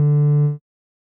Techmino/media/sample/bass/18.ogg at c40a6bfaa02b50296f384cb4e4645dd7ce9f10cb
添加三个简单乐器采样包并加载（之后用于替换部分音效）